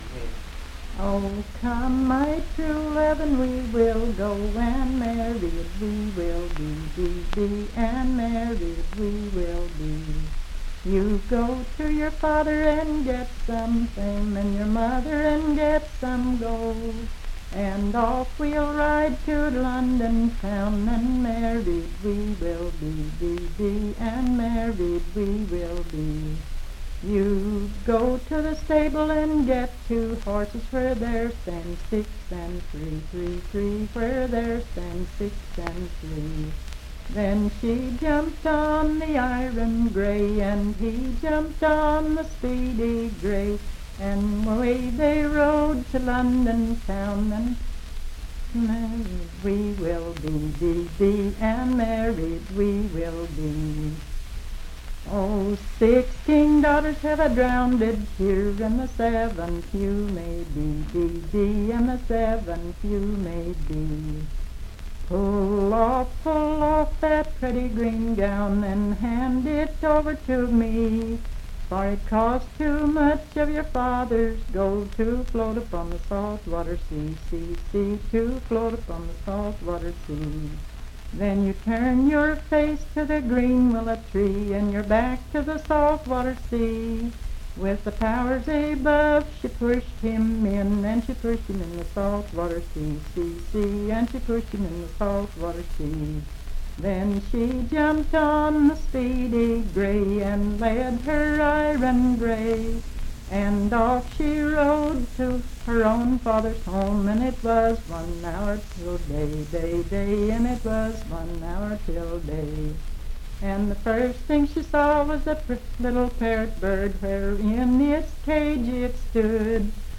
Unaccompanied vocal music
Verse-refrain 9(3-7).
Voice (sung)